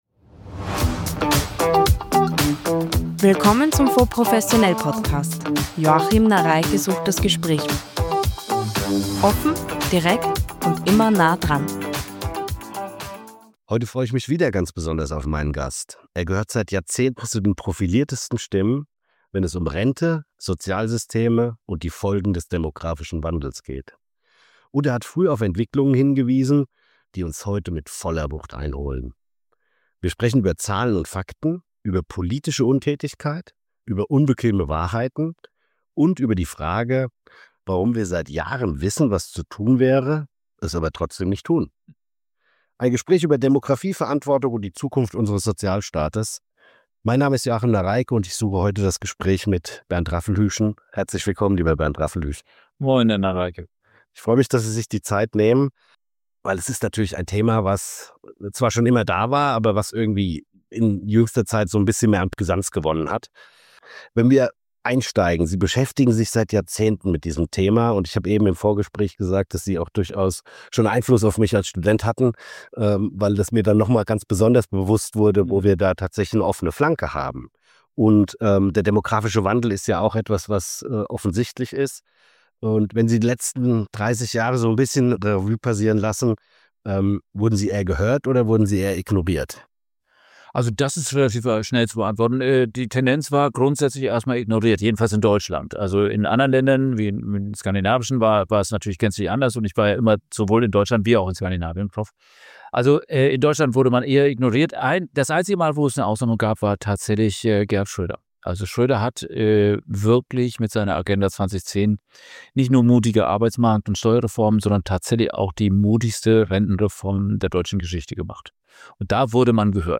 Top-Ökonom Bernd Raffelhüschen spricht im FONDS professionell Podcast über die Rentenkrise, politische Versäumnisse und die Wucht des demografischen Wandels. Ein Gespräch über unbequeme Wahrheiten – und darüber, warum Handeln seit Jahren überfällig ist.